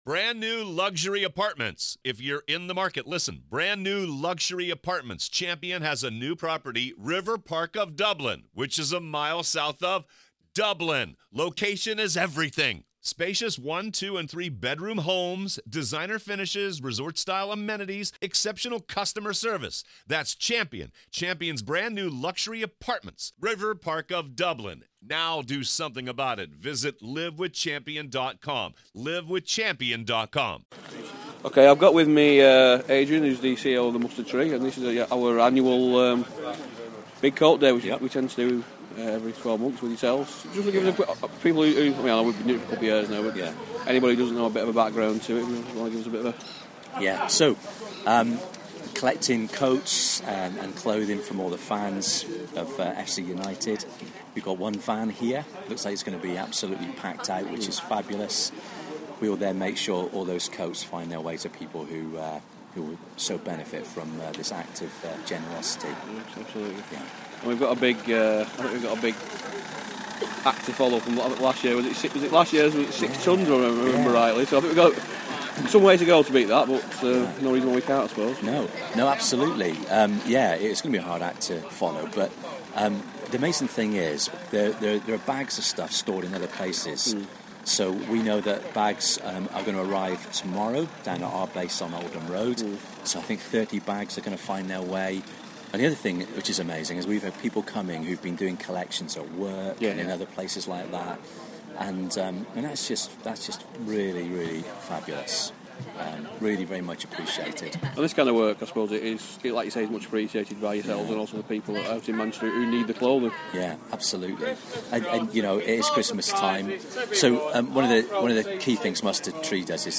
Big Coat Day - Interview